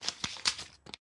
第1包 " 撕纸
描述：我已经记录了撕开一张纸
标签： 翻录 撕裂 撕裂纸
声道立体声